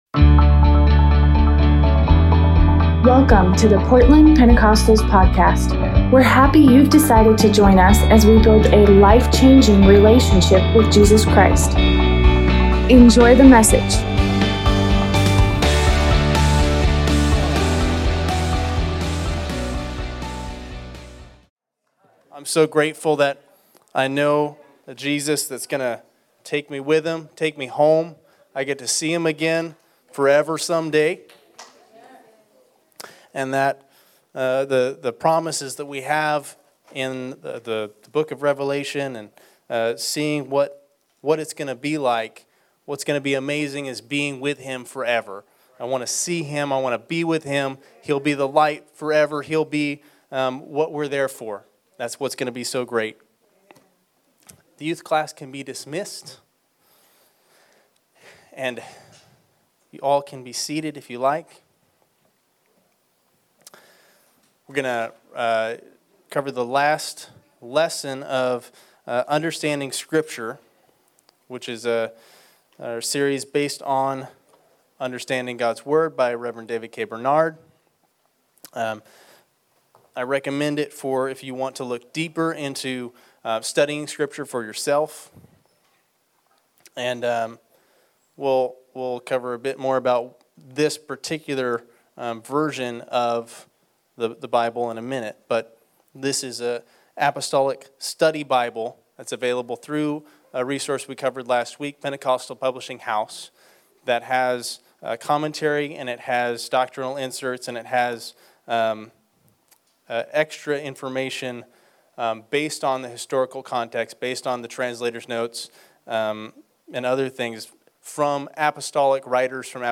Tuesday Bible study